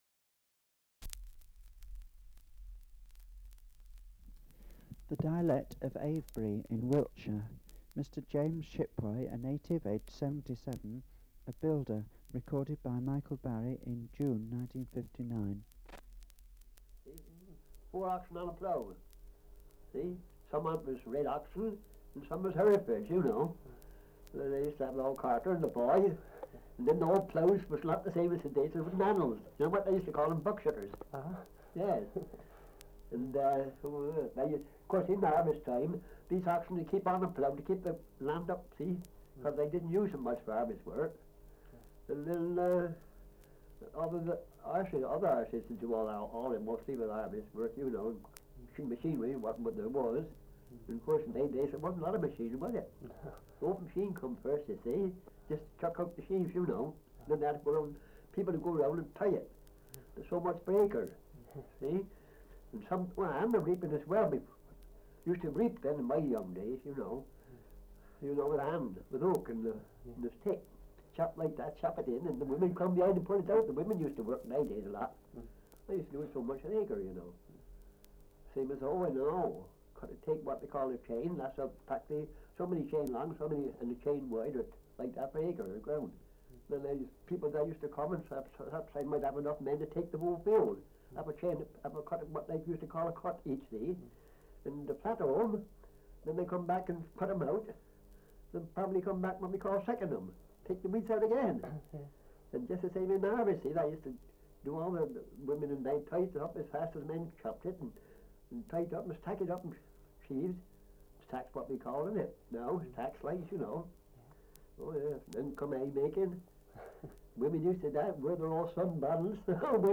Survey of English Dialects recording in Avebury, Wiltshire
78 r.p.m., cellulose nitrate on aluminium